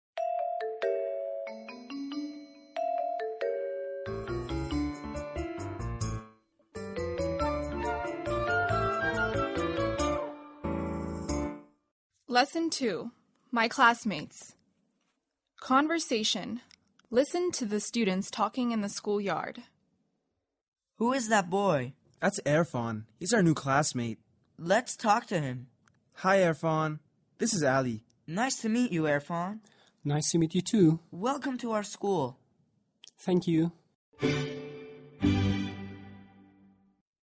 Conversation
P1.L2.conversation.mp3